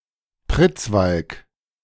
Pritzwalk (German: [ˈpʁɪt͡sˌvalk]